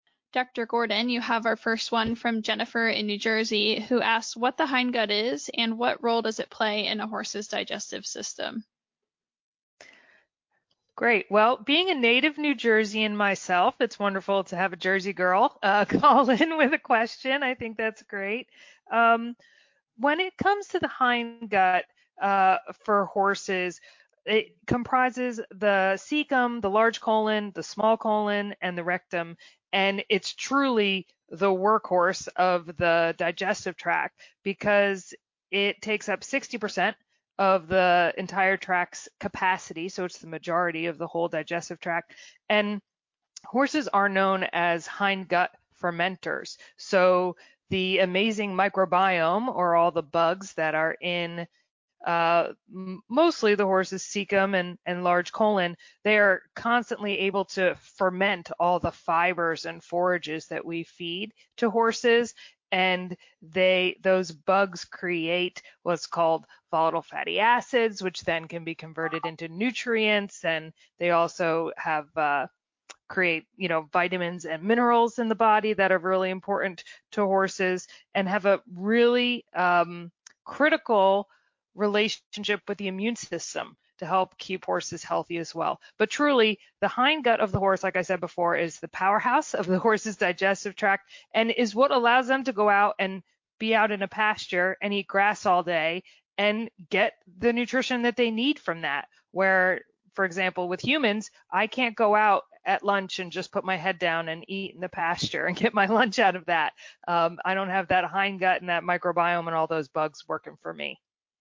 This podcast is an excerpt of our Ask TheHorse Live Q&A, "Understanding Equine Hindgut Health."